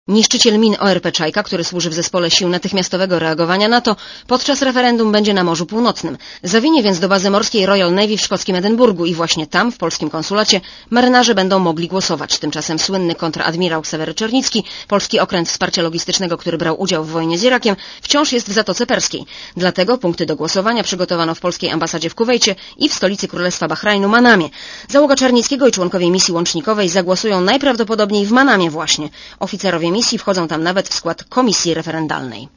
Komentarz audio (130Kb)